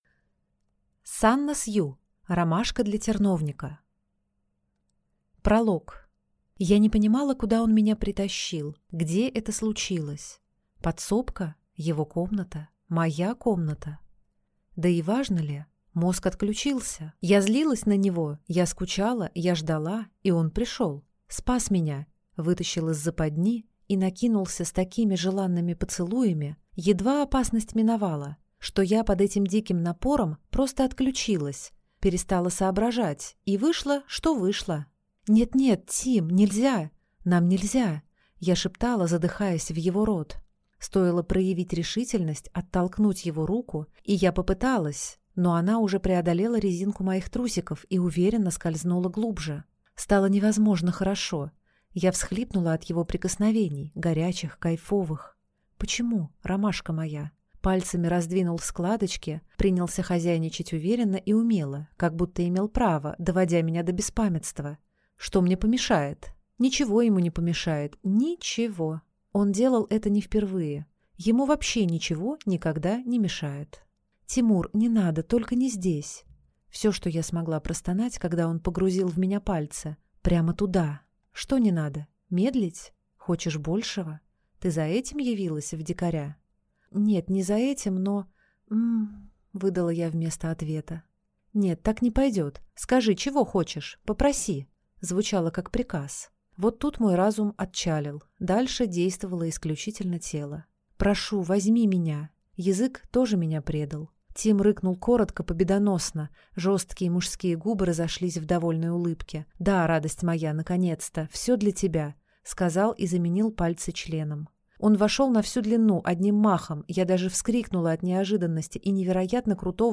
Aудиокнига Ромашка для терновника